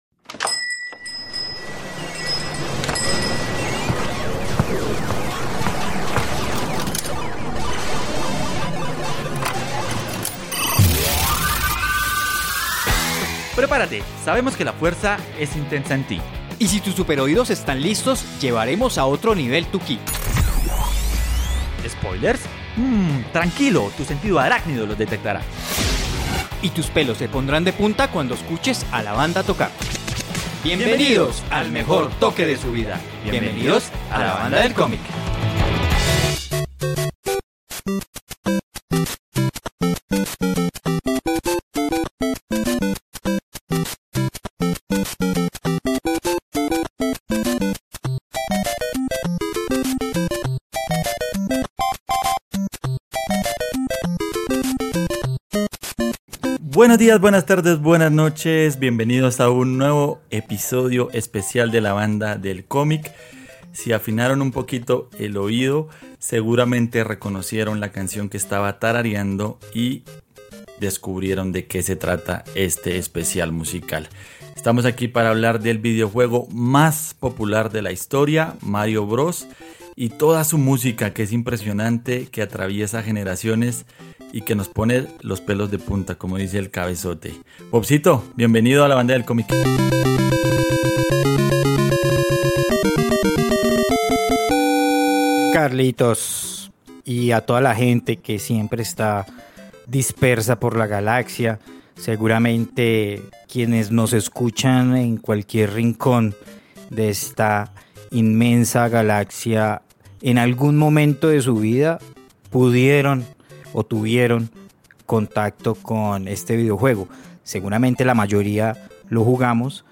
Traemos cuatro canciones emblemáticas de la franquicia, recordamos cómo ha marcado a distintas generaciones, el origen del famoso plomero y hasta te contamos cuál es el récord de tiempo pasando todos los niveles.